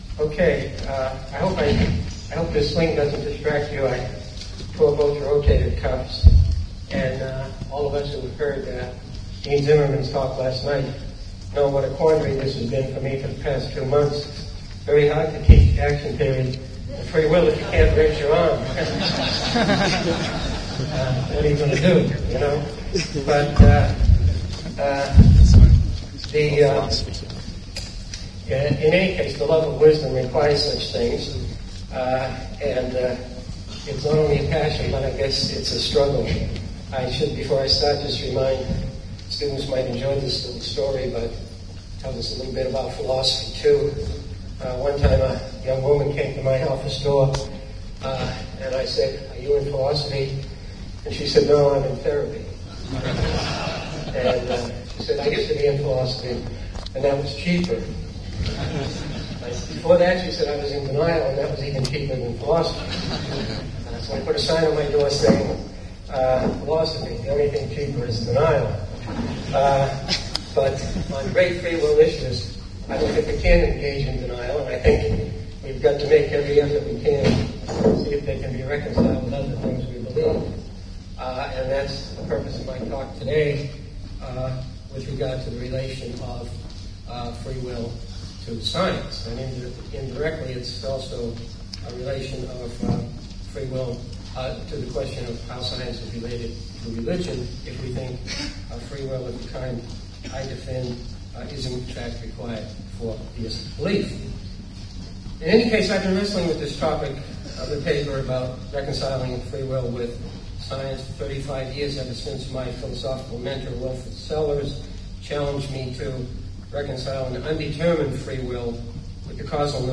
Reflections on Free Will, Determinism and Indeterminism The Dual Regress of Free Will and the Role of Alternative Possibilities (pdf file) via Google Scholar Beta Lecture: Can a Free Will Requiring Ultimate Responsibility be Reconciled with Modern Science?